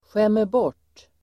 Uttal: [sjemerb'år_t:]